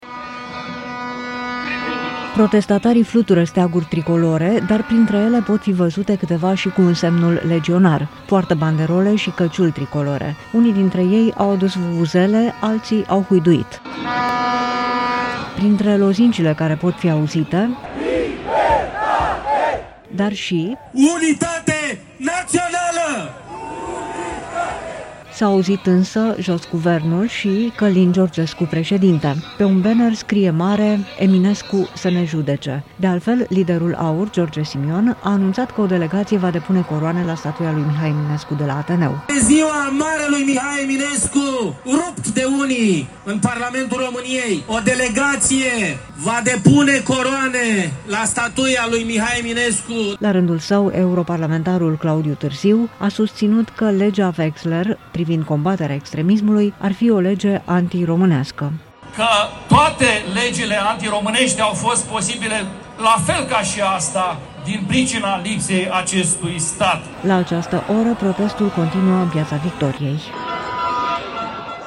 Câteva mii de persoane s-au adunat în Piața Universității și protestează față de așa-numita „lege Vexler” privind combaterea extremismului. Este o manifestație organizată de eurodeputatul Claudiu Târziu și susținută de AUR, partidul din care eurodeputatul a făcut parte până anul trecut.
Unii dintre ei au adus vuvuzele, alții au huiduit.
Printre lozincile care se aud: „Libertate”, „Unitate națională”, „Jos Guvernul!”, „Călin Georgescu președinte!”